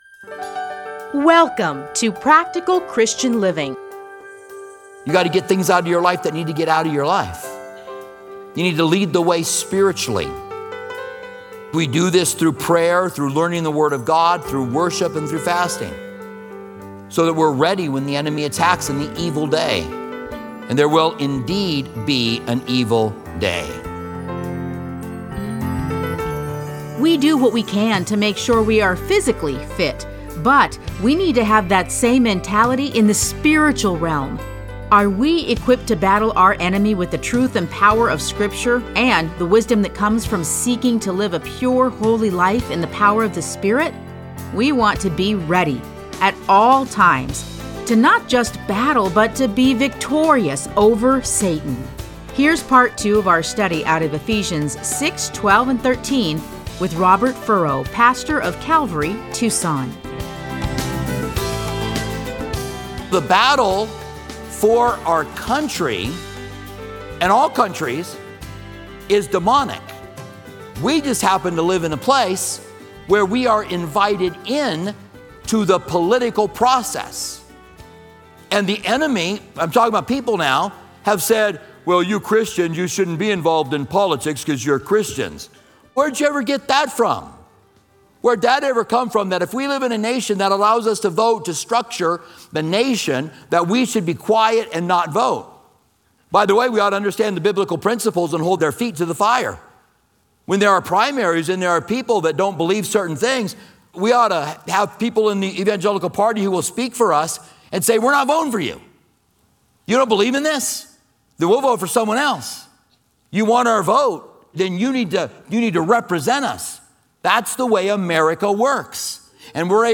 A Study in Ephesians 6:12-13